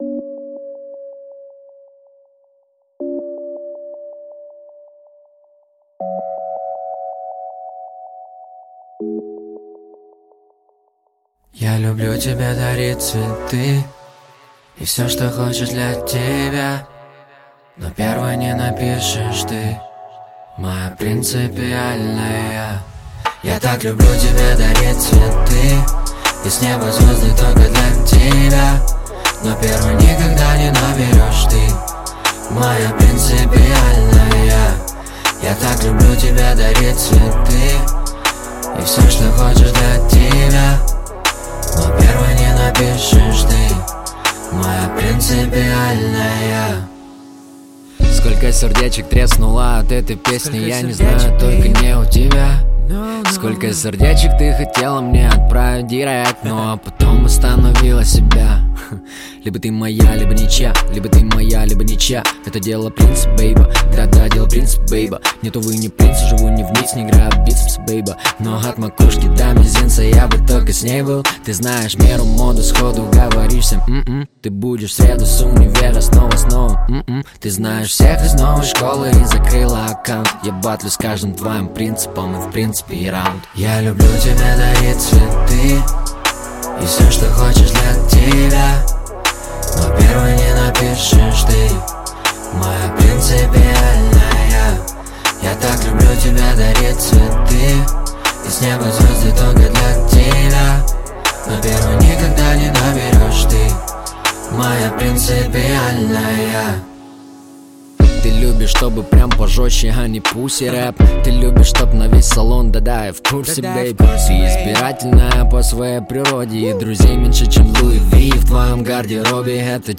Русский рэп
Жанр: Русский рэп / R & B